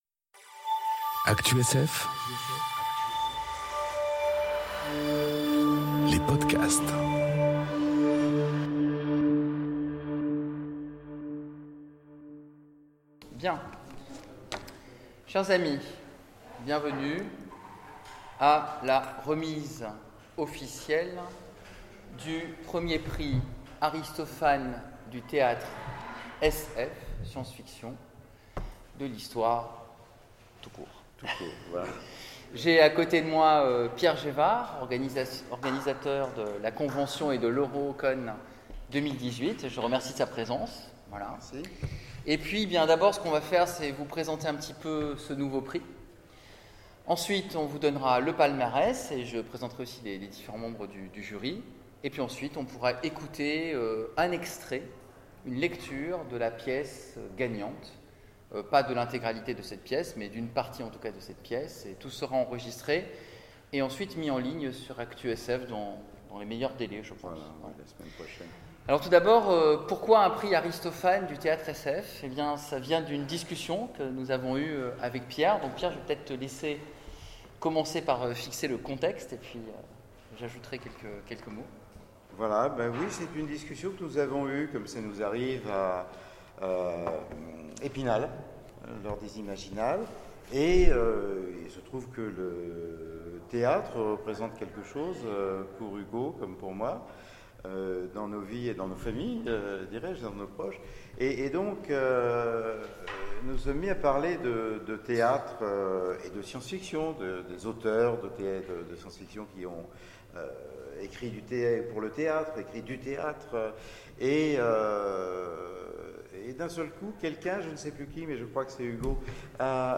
Eurocon 2018 : Remise du prix Artistophane